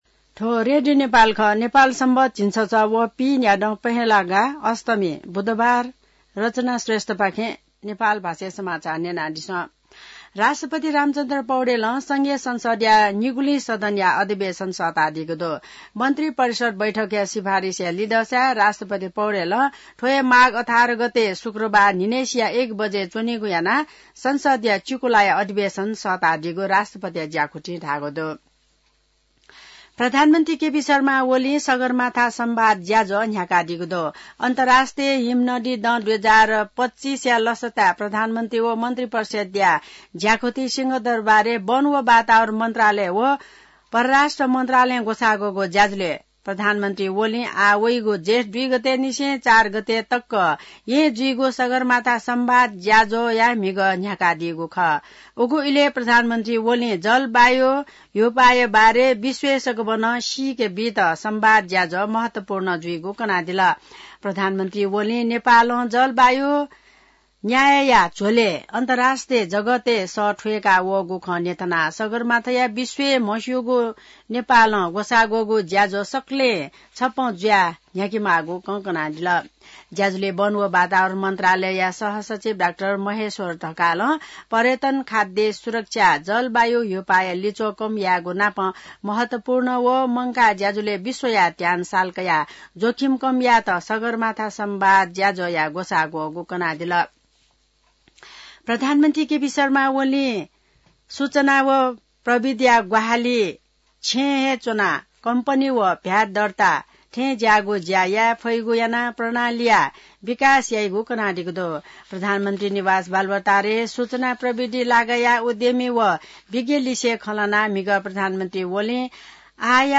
नेपाल भाषामा समाचार : १० माघ , २०८१